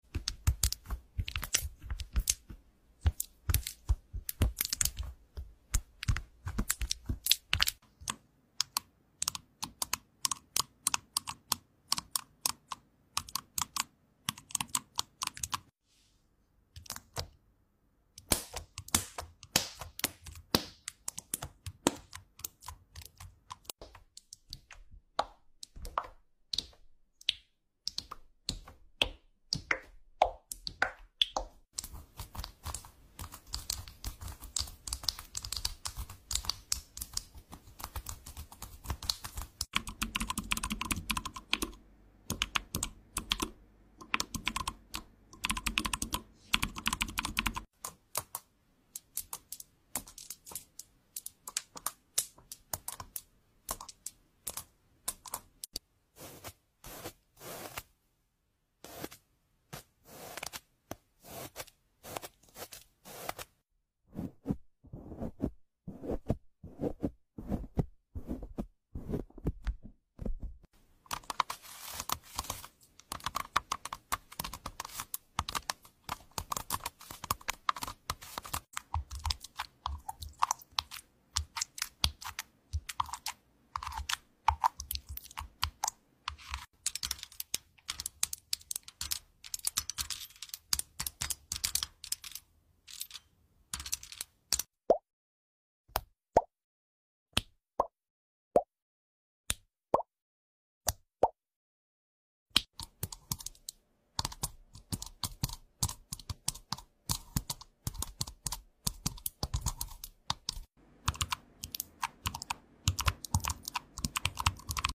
🧠 AI Keyboard Typing ASMR sound effects free download
Satisfying clicks you didn’t know you needed… 👀🎧 Turn up the volume and feel the rhythm!